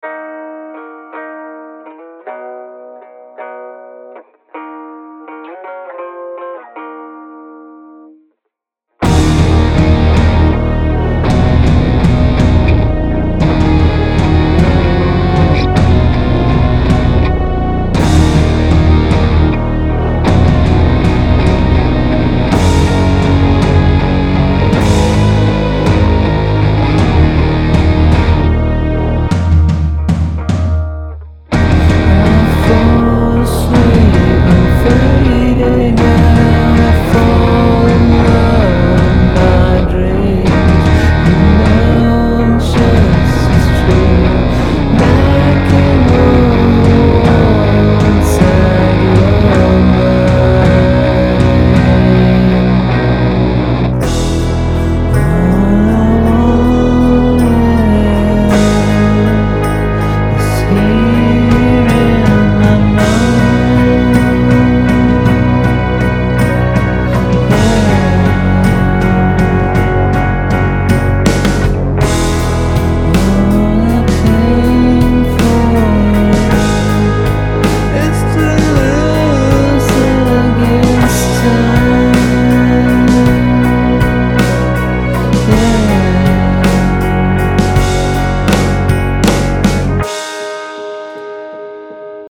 Neuer Sound, neue Ideen.
Ich mag 6/8. Und Key-Changes.
Der Wechsel von D#-Moll auf E-Dur klappt ganz gut und fühlt sich normal an.